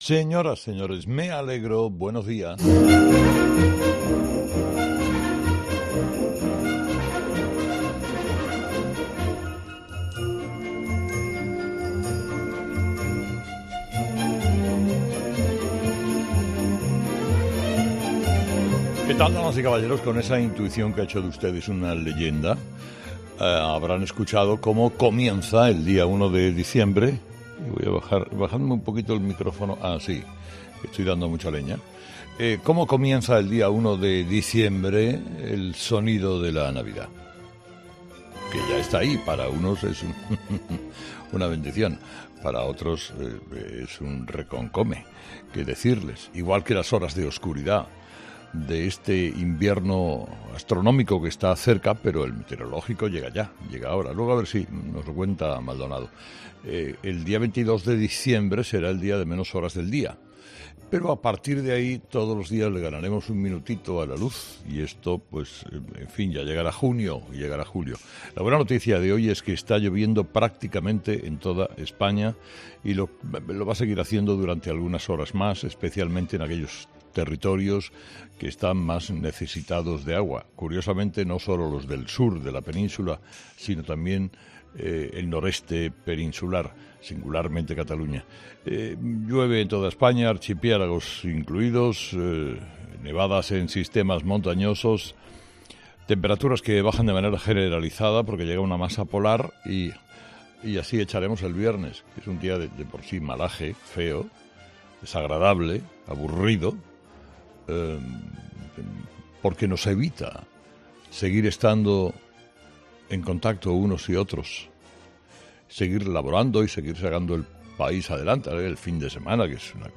Escucha el análisis de Carlos Herrera a las 06:00 en Herrera en COPE del viernes 1 de diciembre
Carlos Herrera, director y presentador de 'Herrera en COPE', comienza el programa de este viernes analizando las principales claves de la jornada que pasan, entre otras cosas, por el día negro para Pedro Sánchez con García Ortiz y Calviño.